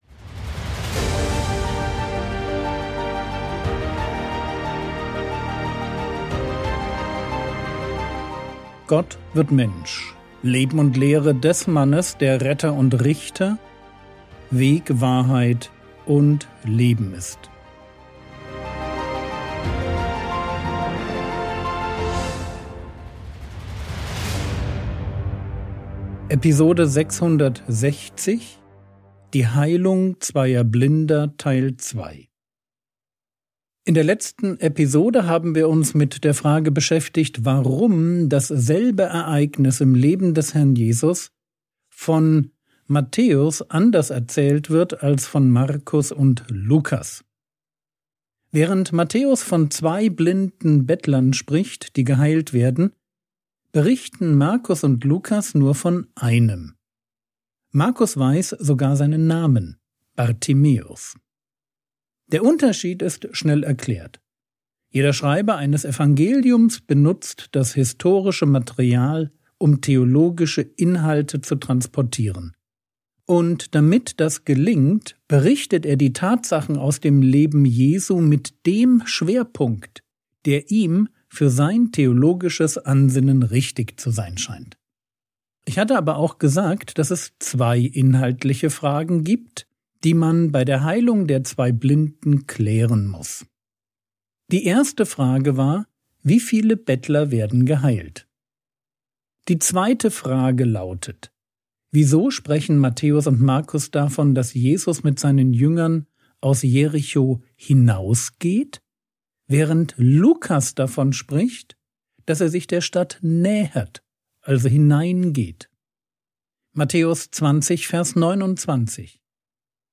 Episode 660 | Jesu Leben und Lehre ~ Frogwords Mini-Predigt Podcast